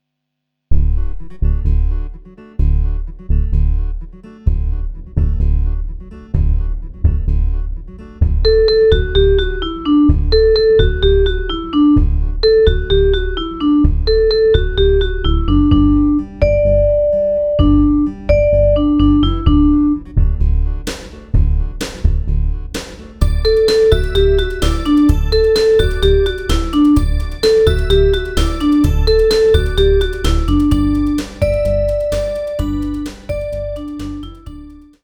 Recueil pour Violoncelle